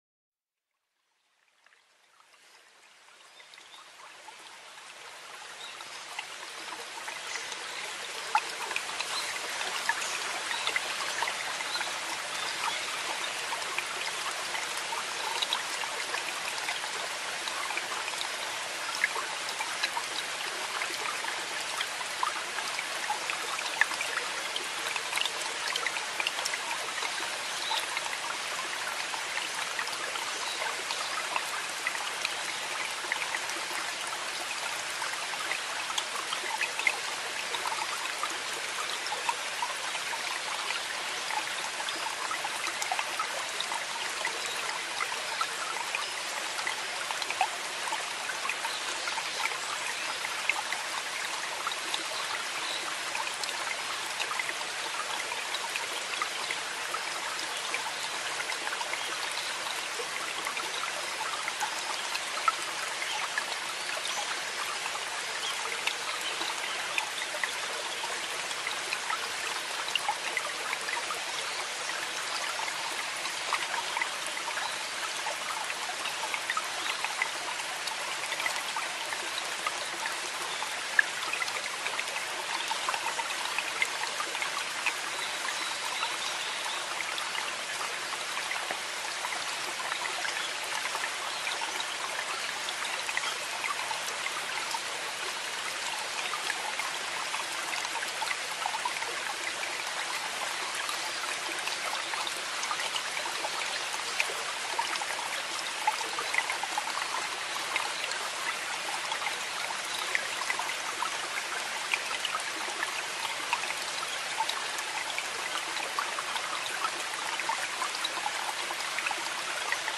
Sound of rain
Everyone knows the characteristic sound of raindrops falling in a puddle.
QUA.relaxing_rain_in_puddles_besoundasleep.inettools.net_.cutmp3_.mp3